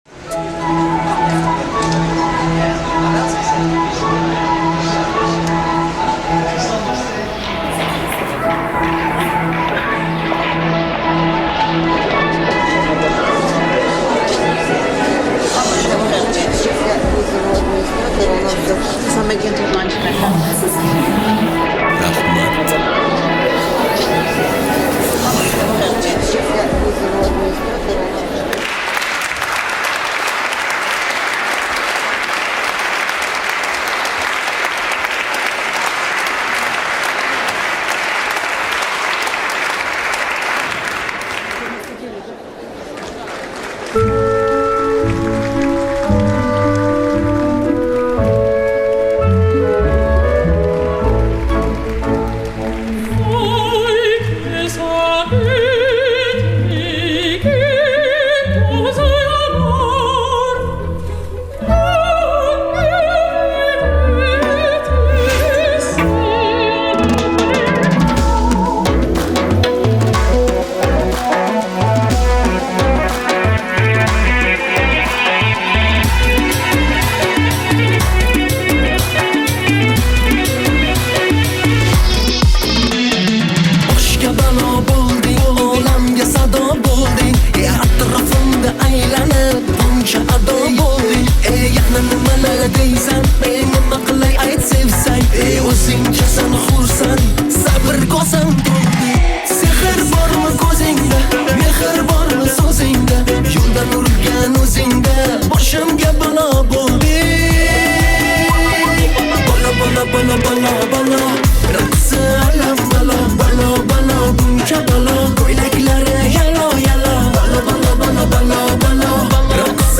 Жанр: Узбекские